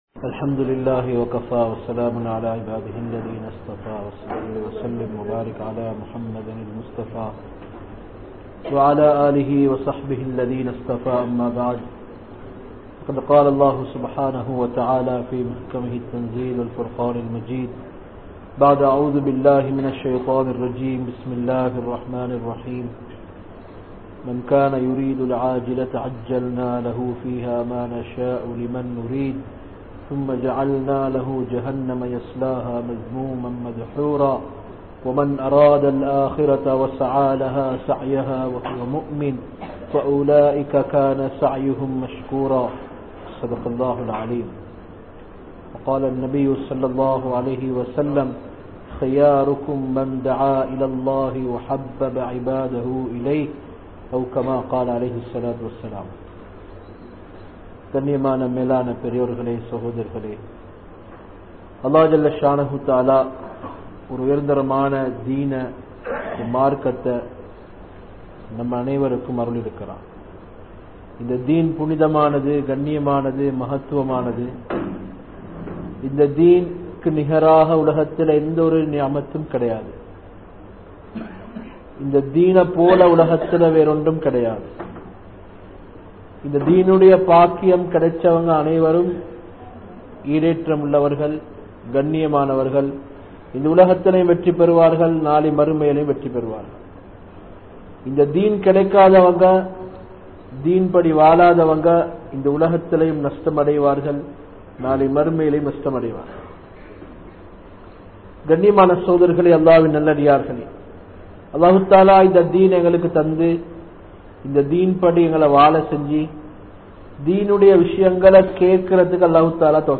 Marumaikkaaha Vaalungal (மறுமைக்காக வாழுங்கள்) | Audio Bayans | All Ceylon Muslim Youth Community | Addalaichenai